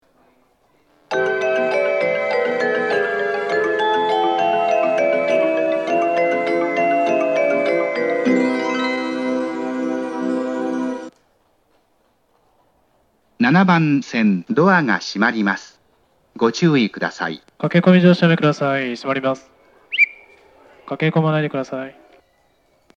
発車メロディー
余韻切りです。こちらも余韻まで鳴りやすいです。